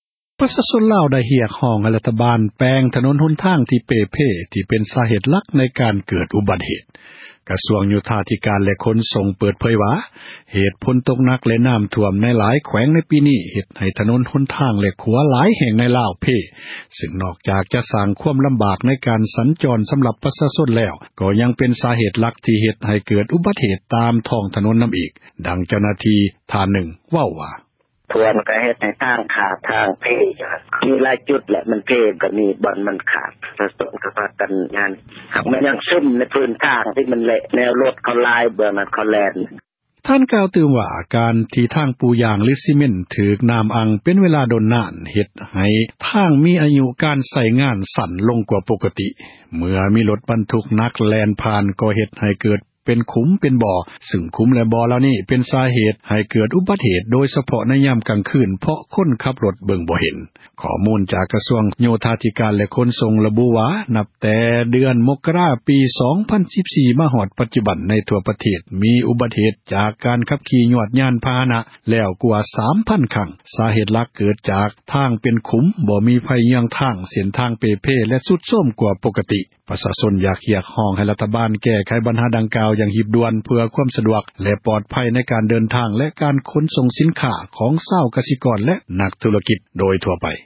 ກະຊວງ ໂຍທາທິການ ແລະ ຂົນສົ່ງ ເປີດເຜີຍວ່າ ເຫດການ ຝົນຕົກ ນ້ຳຖ້ວມ ຫຼາຍແຂວງ ໃນປີນີ້ ເຮັດໃຫ້ ຖນົນຫົນທາງ ແລະ ຂົວ ຫຼາຍບ່ອນ ໃນລາວ ເພພັງ ເຮັດໃຫ້ ການ ເດີນທາງ ລຳບາກ ແລະ ຍັງເປັນ ສາເຫດ ເຮັດໃຫ້ເກີດ ອຸບັດຕິເຫດ ນຳອີກ. ດັ່ງ ເຈົ້າຫນ້າທີ່  ໂຍທາ ທິການ ແລະ ຂົນສົ່ງ ເວົ້າວ່າ: